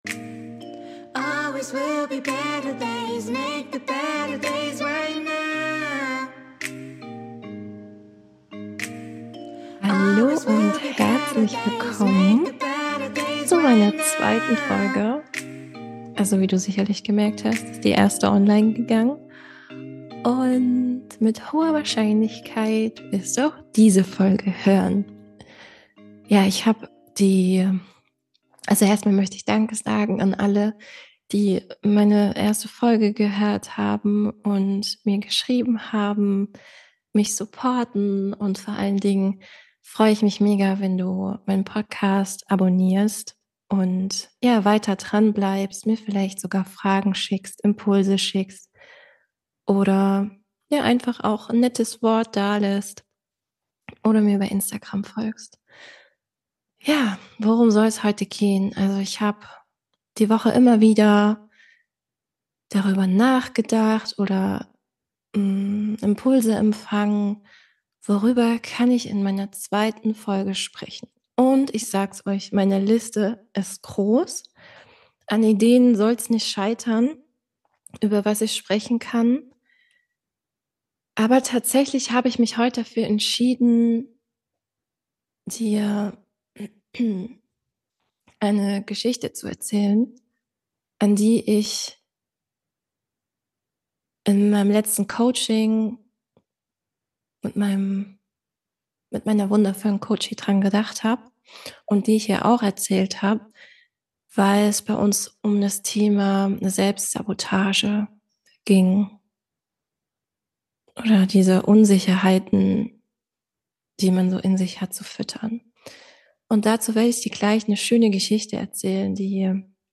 In dieser Folge, lade ich dich ein einer Geschichte zu lauschen, in der es scheinbar um zwei Wölfe geht. Ich lade dich ein mittels Meditation (ca.21 Min.) selbst herauszufinden, welchen Wolf du fütterst und zeige dir neue Perspektiven auf, den energetischen „Shit“ anderer nicht mehr zu essen, um dich weiterhin damit zu nähren.